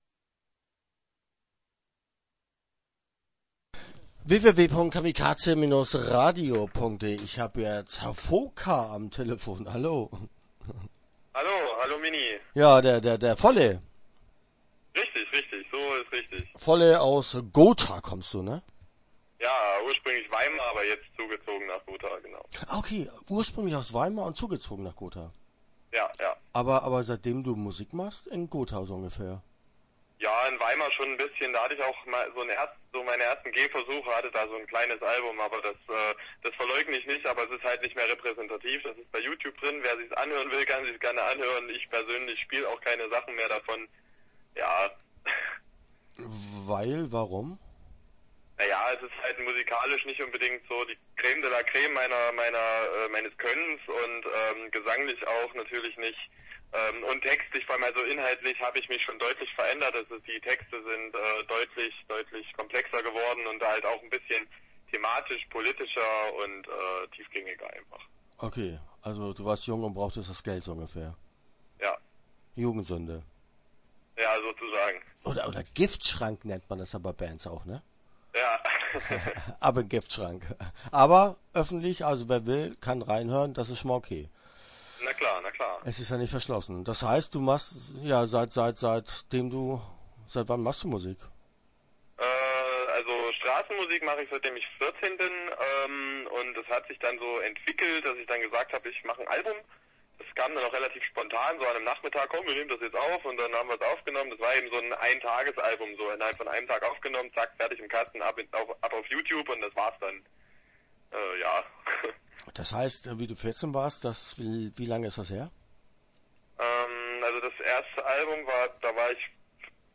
Start » Interviews » VK-Liedermaching